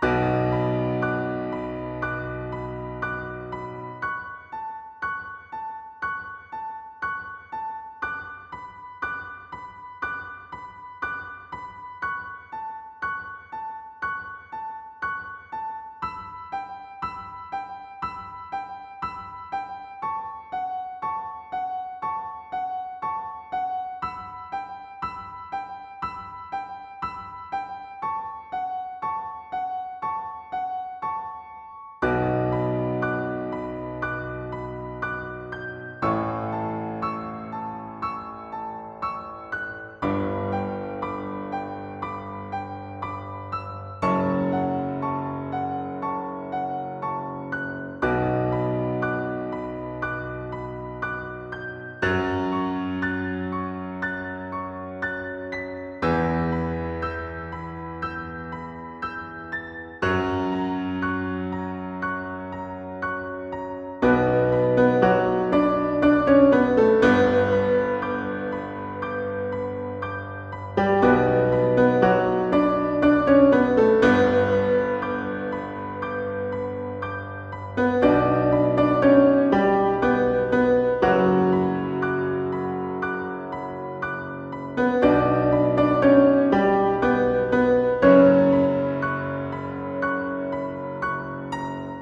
Musique pour la cinématique de fin :